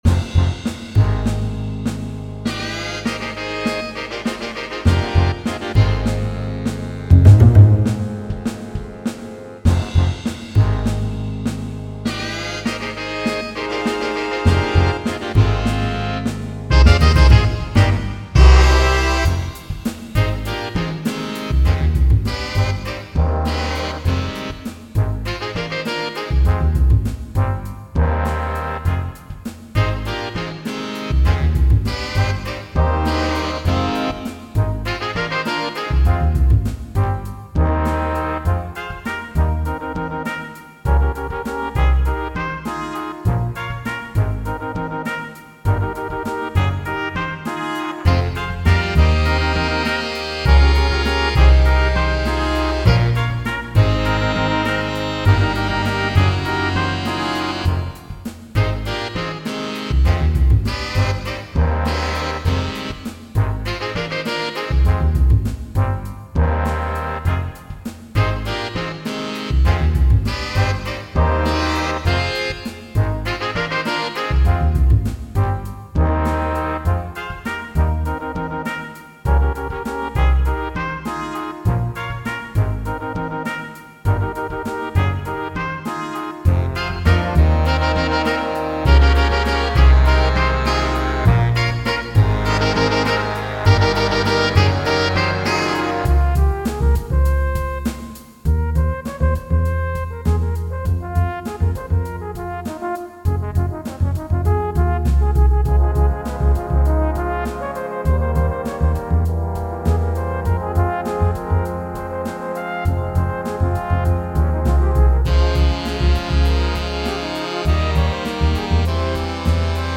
All audio files are computer-generated.
Piano requires comping.